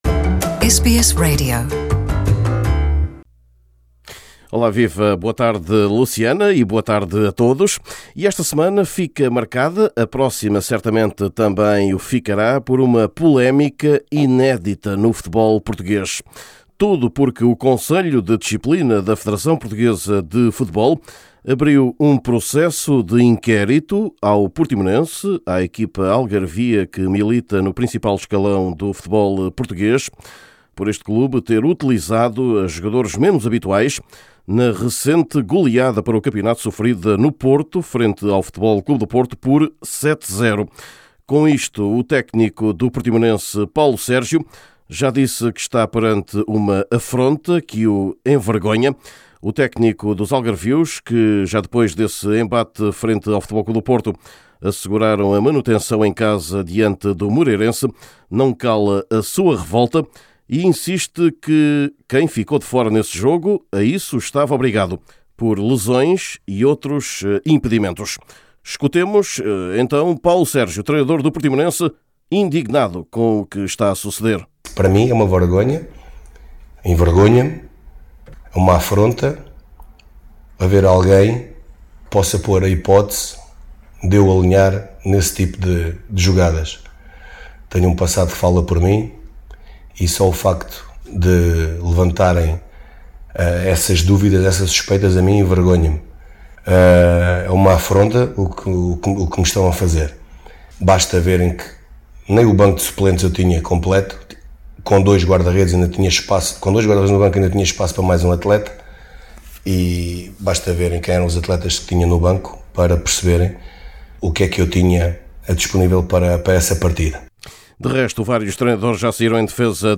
Neste boletim esportivo, acompanhe também os novos campeões europeus de sub-19, a equipa do Benfica, que foi homenageada em Lisboa e jogará agora uma inédita “Intercontinental”, em agosto, no Uruguai. Abordamos, ainda, o mercado do futebol ou as modalidades: Ralie de Portugal, MotoGP, Rugby ou um escândalo no ciclismo nacional.